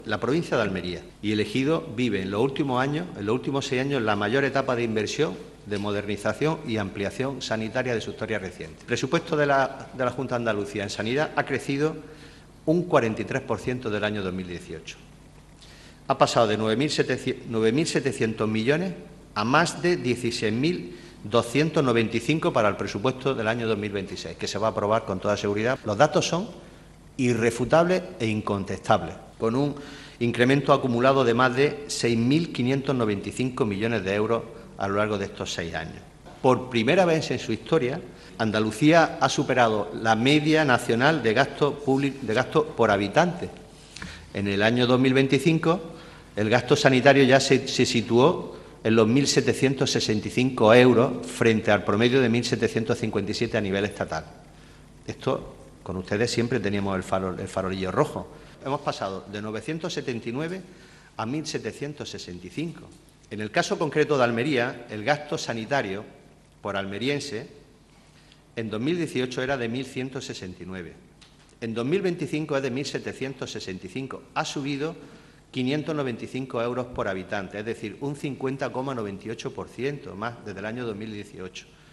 Durante el Pleno celebrado hoy, Góngora ha recordado al PSOE que “no se está desmantelando el Sistema Sanitario Público Andaluz.